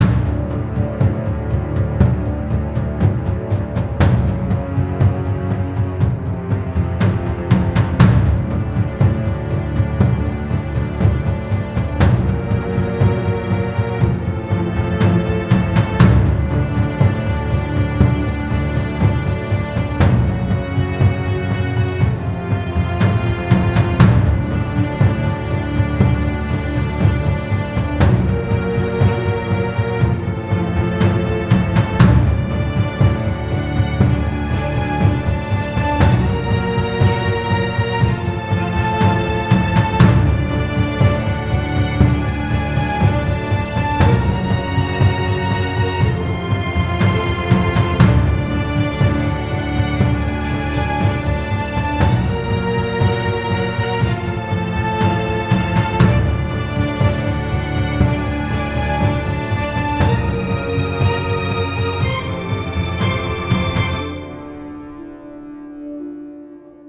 Ambient music